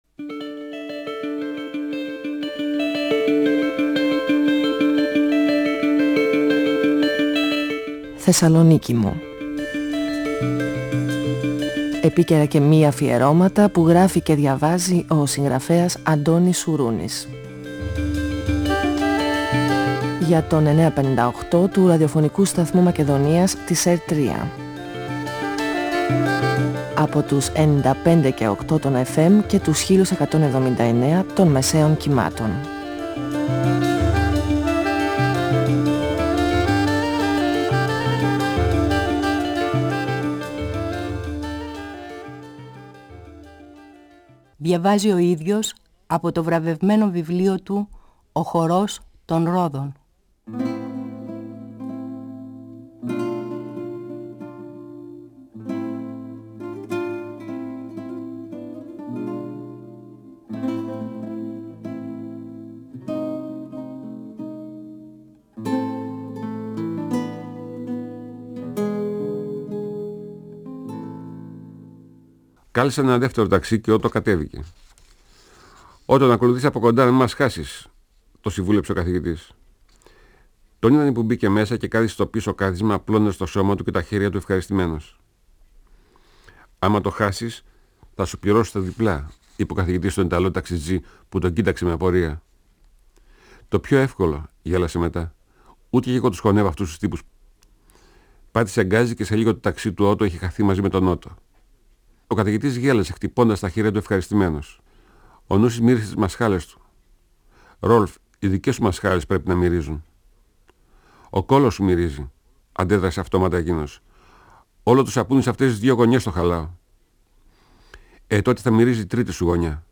Ο συγγραφέας Αντώνης Σουρούνης (1942-2016) διαβάζει από το βιβλίο του «Ο χορός των ρόδων», εκδ. Καστανιώτη, 1994. Ο Νούσης, ο Καθηγητής και ο Ρολφ πηγαίνουν με ταξί στη Γιουγκοσλαβία για να παίξουν στο καζίνο.